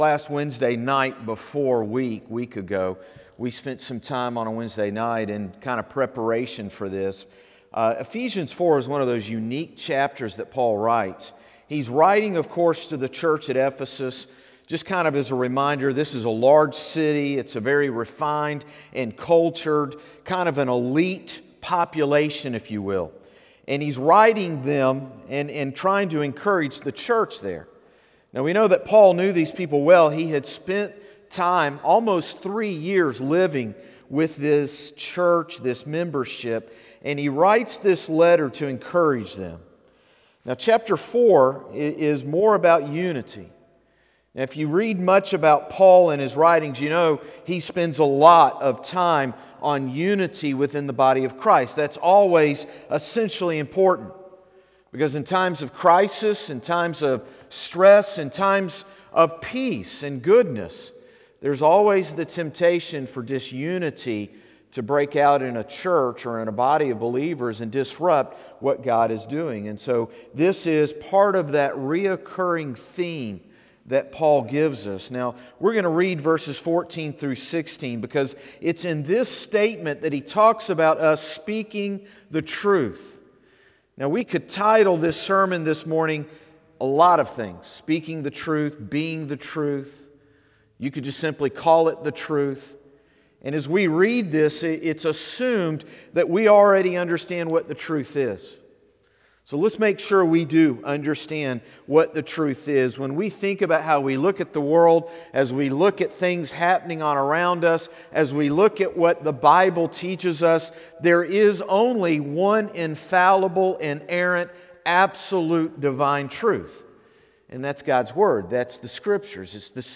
Morning Service - Speaking the Truth | Concord Baptist Church
Sermons - Concord Baptist Church